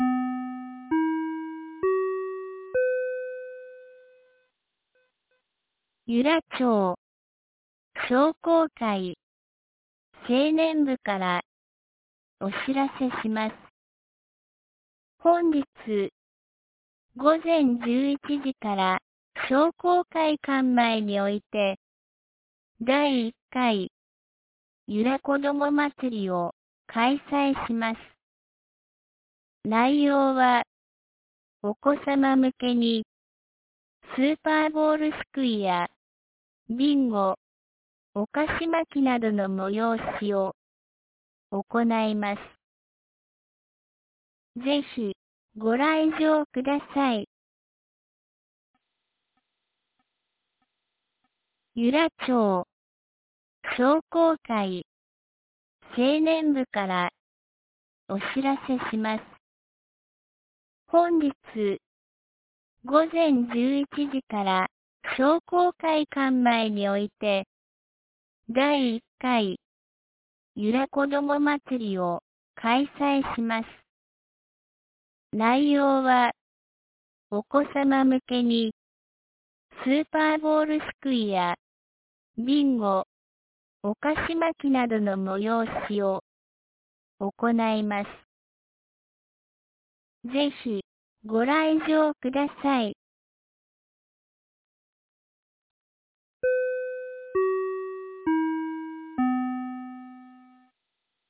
2025年05月25日 07時52分に、由良町から全地区へ放送がありました。